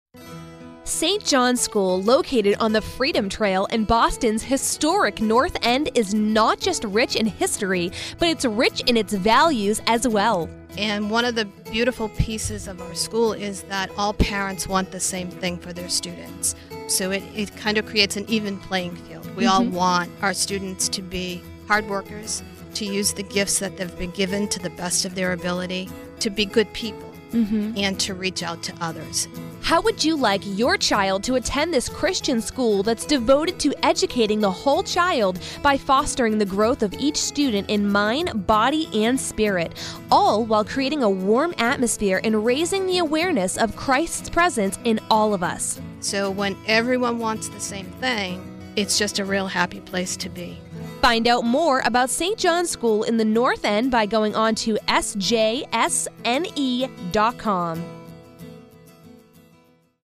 Radio Interview with Principal